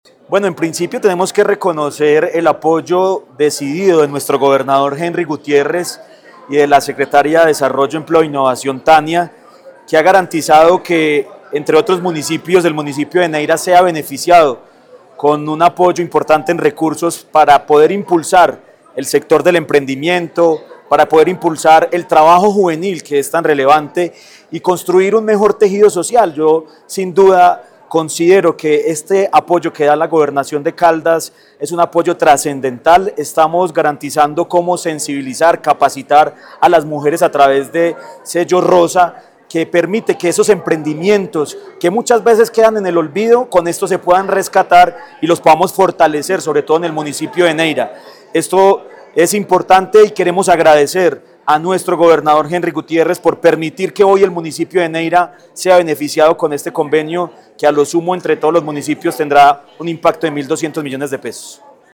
Jhon Jairo Castaño Flórez, alcalde del municipio de Neira.
Jhon-Jairo-Castano-Florez-alcalde-del-municipio-de-Neira.mp3